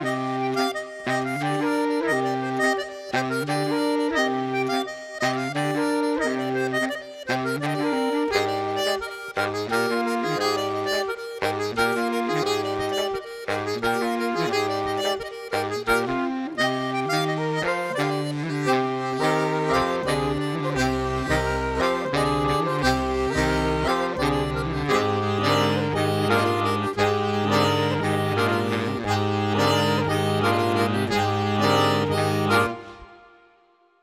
Hanter dro